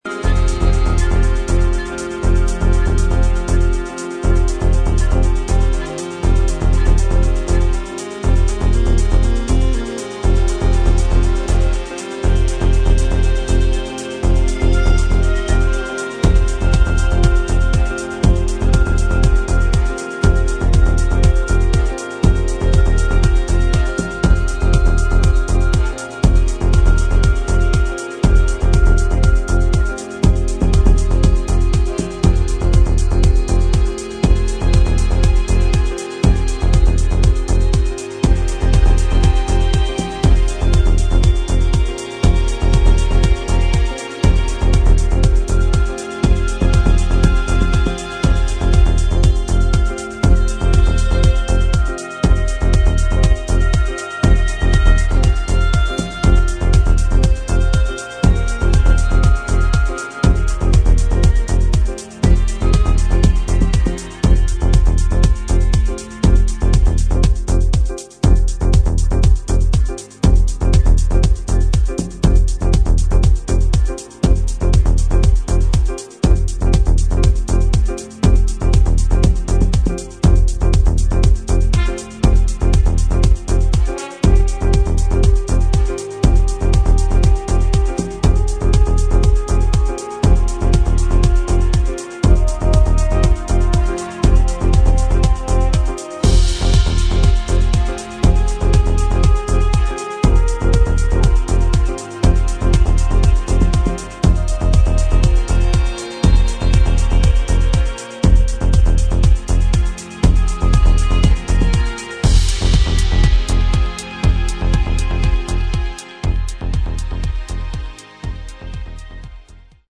[ TECHNO / DETROIT / CLASSIC / EXPERIMENTAL ]